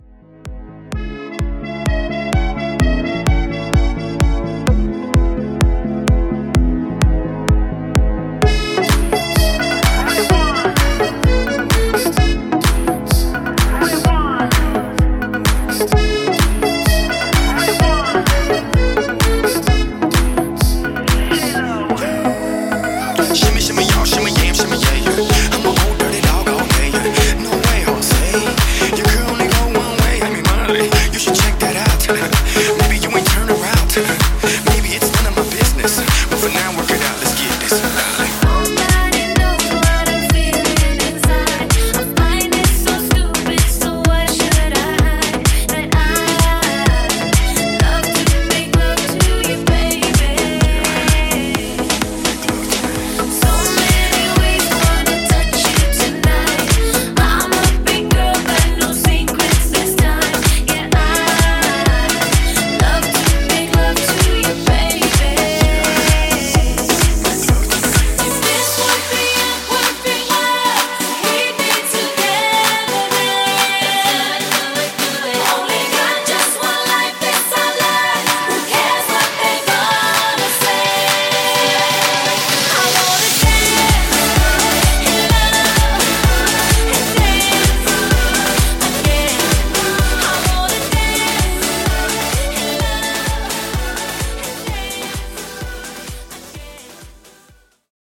Party Mashup)Date Added